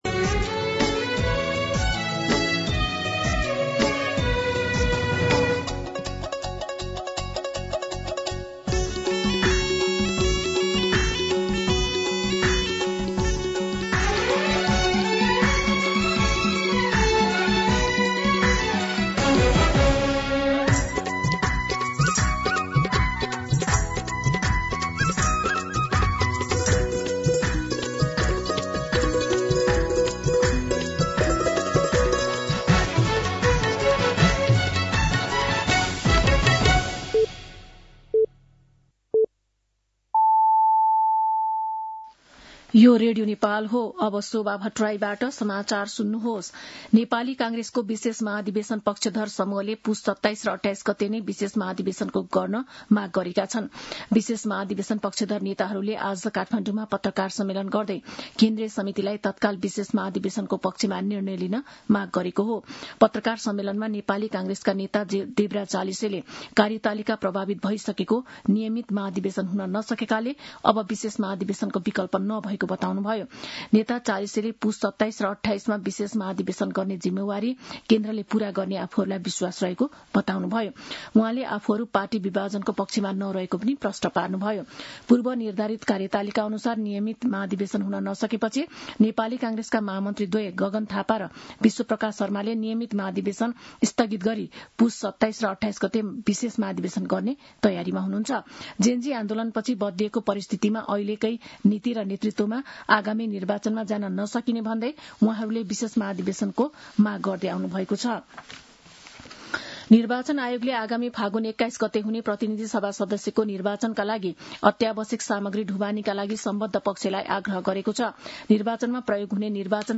दिउँसो १ बजेको नेपाली समाचार : १८ पुष , २०८२